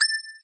ping_4.ogg